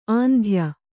♪ āndhya